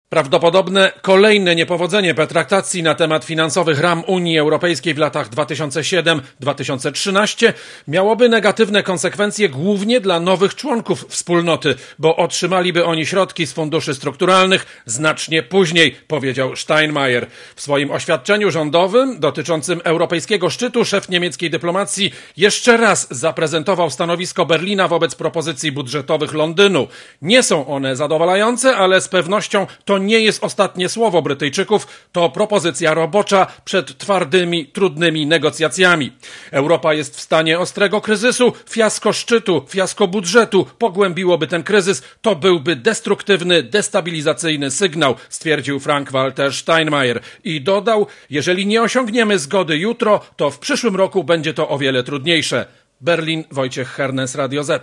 Taką opinię wyraził dziś w Bundestagu niemiecki minister spraw zagranicznych Frank-Walter Steinmaier.